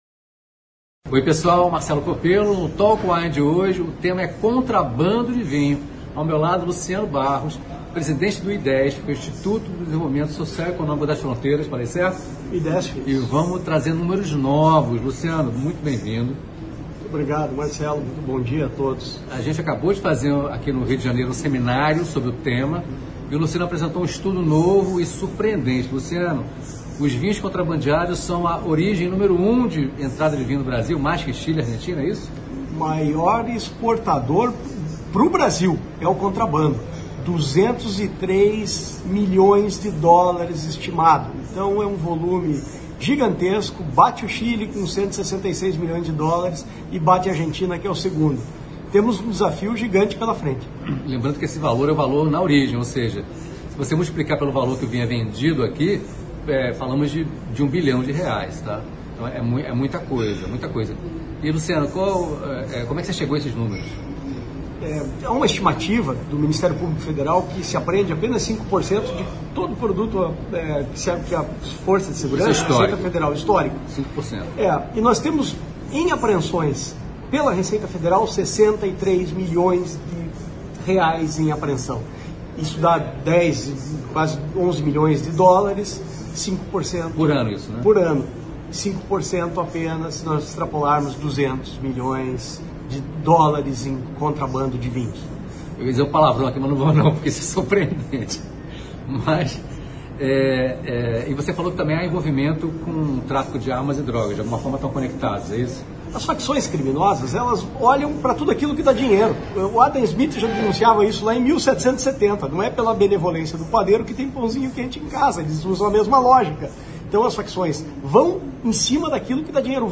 fala sobre o contrabando de vinhos no Brasil em conversa